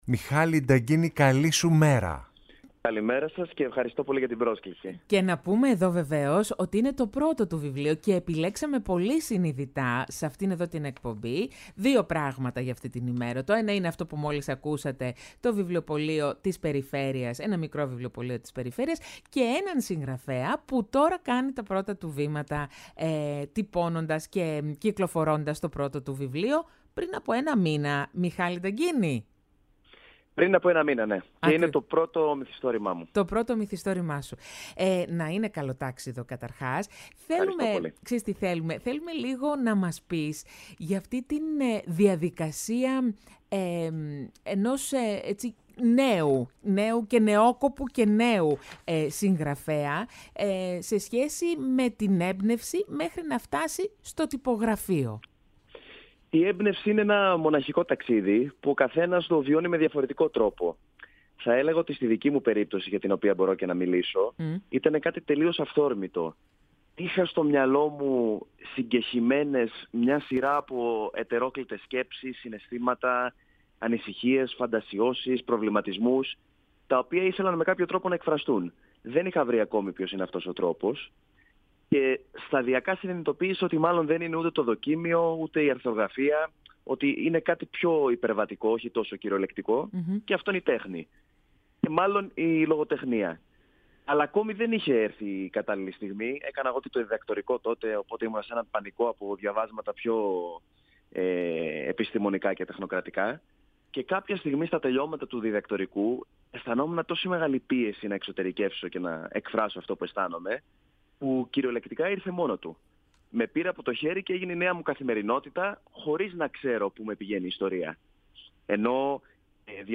Σε μια ουσιαστική και ανθρώπινη συζήτηση, μίλησε για τη διαδρομή της έμπνευσης, τη μοναχική αλλά και λυτρωτική διαδικασία της συγγραφής, καθώς και για την ανάγκη του δημιουργού να μοιραστεί τις σκέψεις, τις ιστορίες και τις ιδέες του δημόσια, μέσα από τις σελίδες του δικού του βιβλίου.